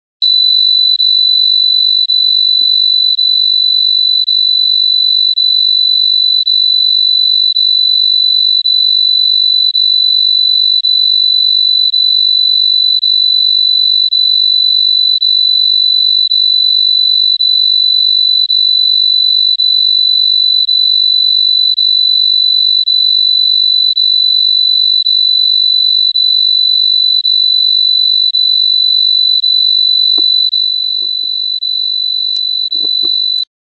Звуки отпугивающие
В этой подборке собраны эффективные звуки для отпугивания нежелательных гостей: ультразвуковые сигналы, резкие шумы, природные звукоэффекты.
Звук для отпугивания комаров